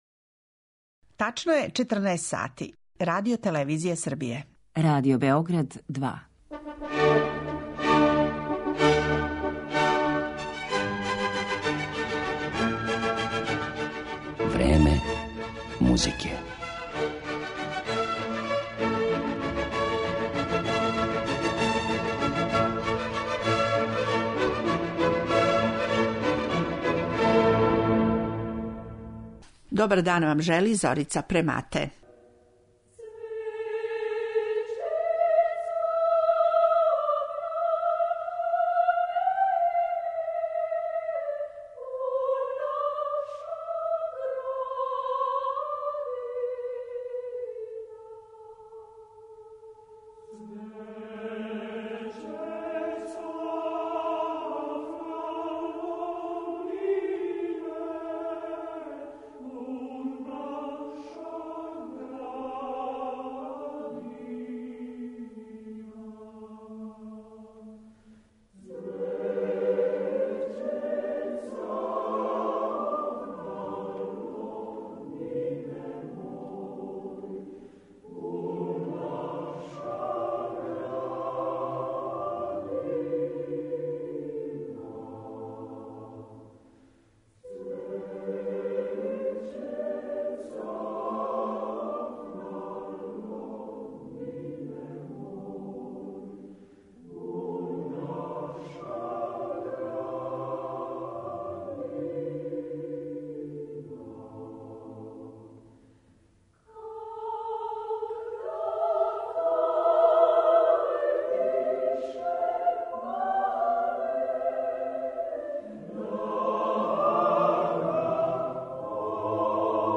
Осим Мокрањчевог изворника, чућете и како су ову мелодију у својим остварењима употребили: Петар Коњовић, Предраг Милошевић, Божидар Трудић, Душан Радић и Миливоје Црвчанин.